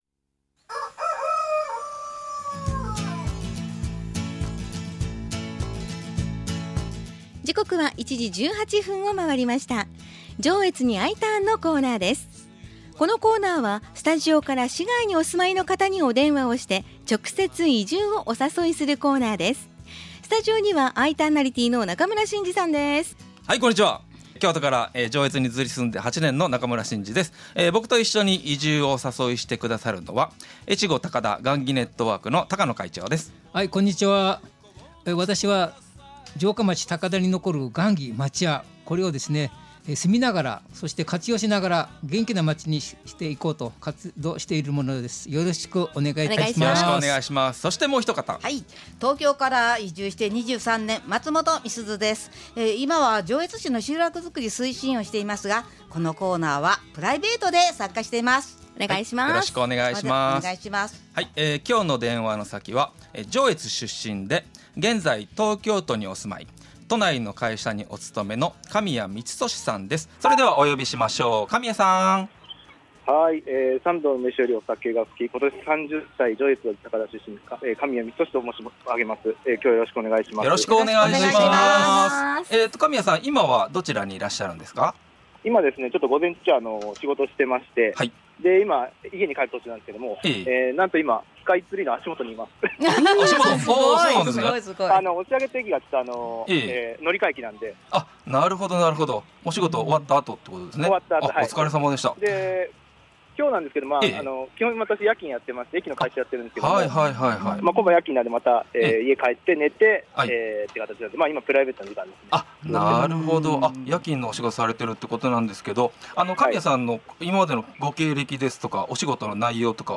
FM-Jのスタジオから市外にお住まいの方に電話をして、直接移住をお誘いするコーナーです。